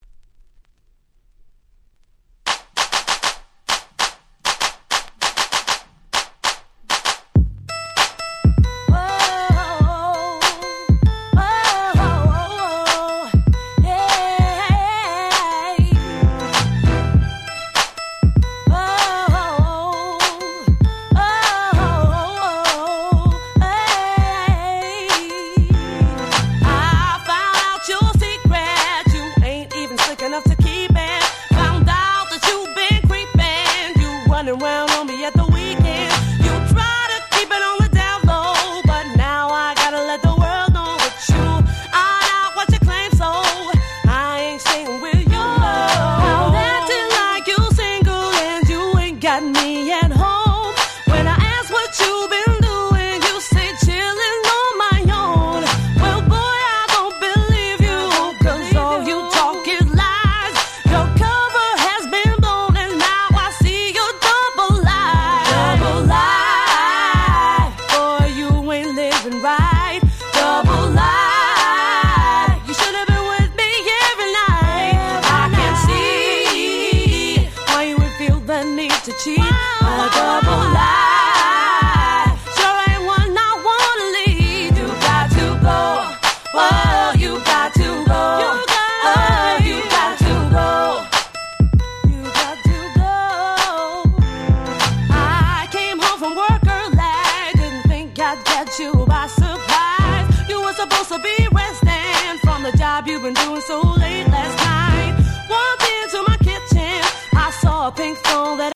06' Very Nice R&B EP !!